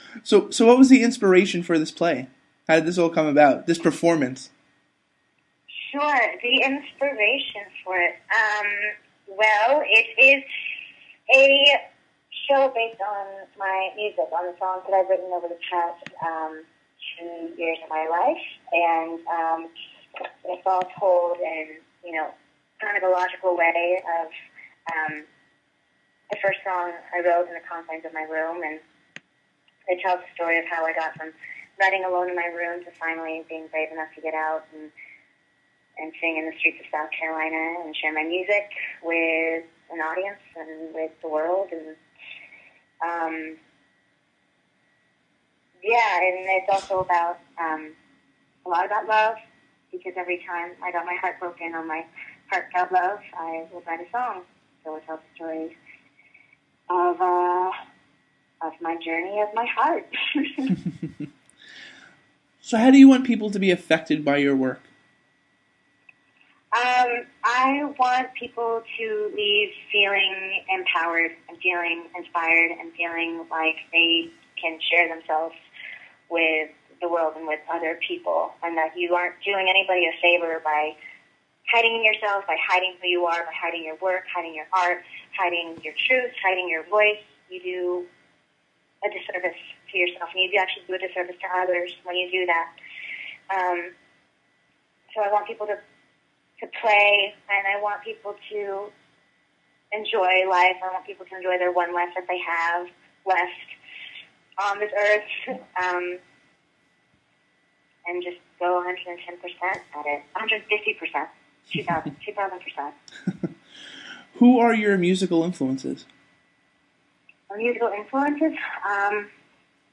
Review Fix Exclusive: Interview